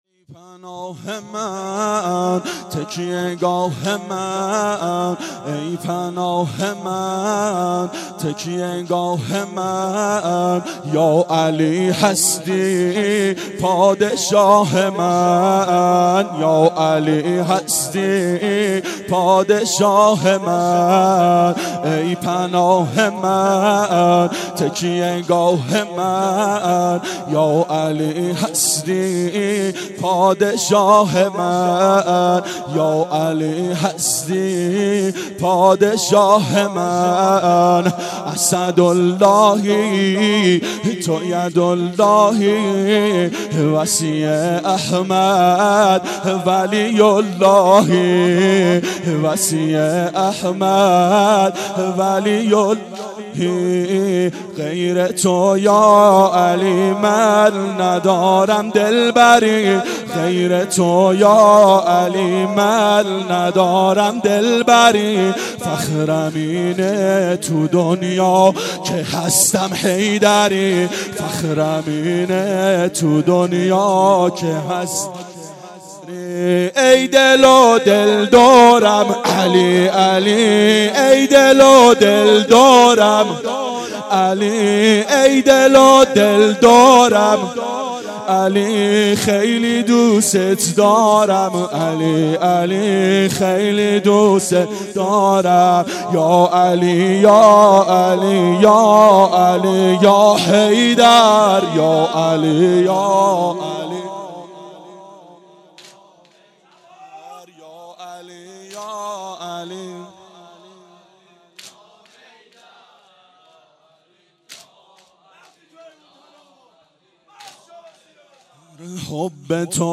• فاطمیه اول 92 هیأت عاشقان اباالفضل علیه السلام منارجنبان